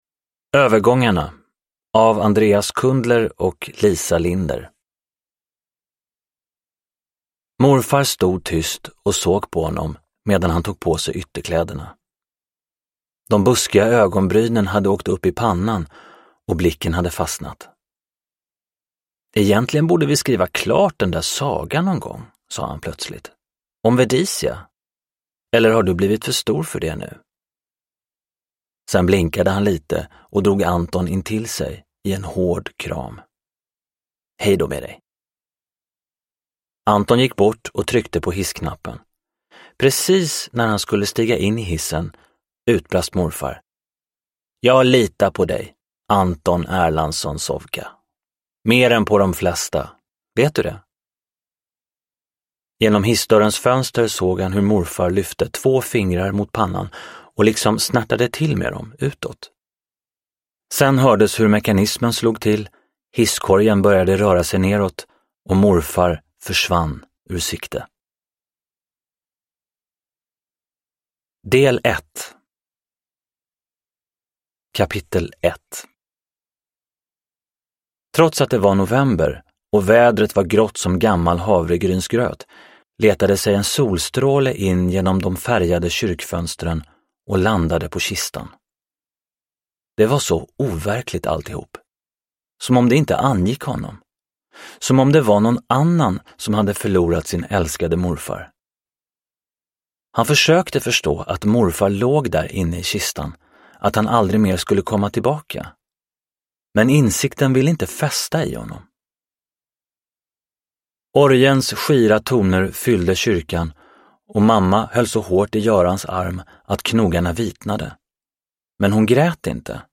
Övergångarna – Ljudbok – Laddas ner